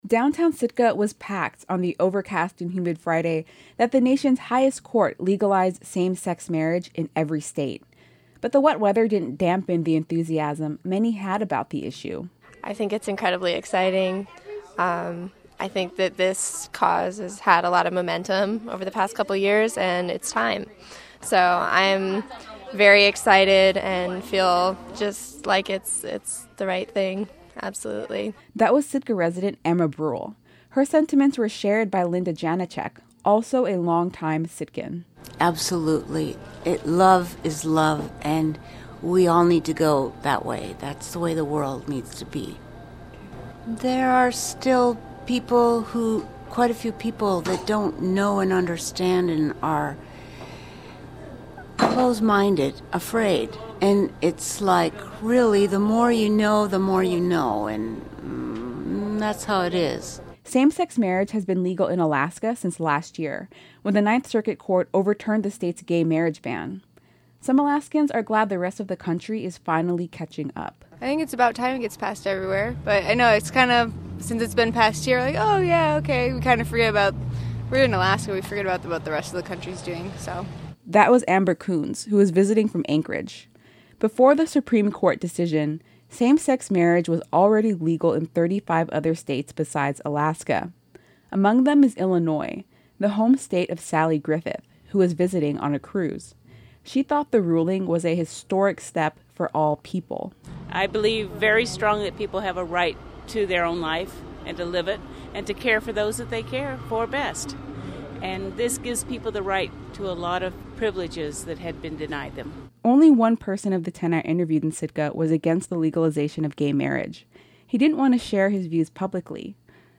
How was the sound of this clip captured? Downtown Sitka was packed on the overcast and humid Friday that the nation’s highest court legalized same-sex marriage in every state.